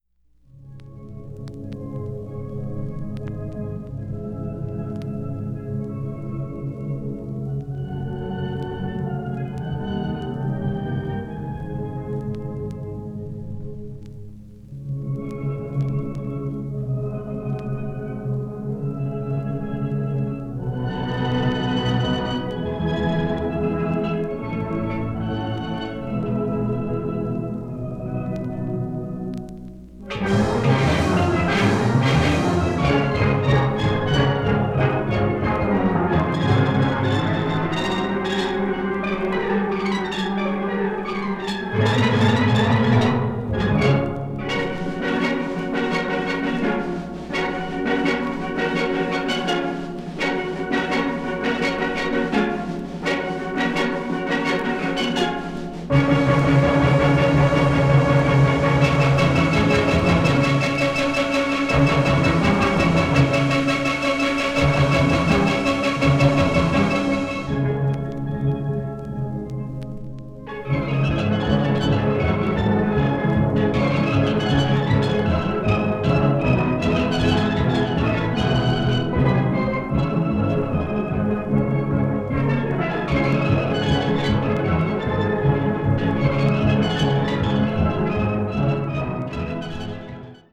media : EX-/VG+(わずかなチリノイズ/一部軽いチリノイズ/軽いプチノイズが入る箇所あり)
carib   steel pan   trinidad tobago   tropical   world music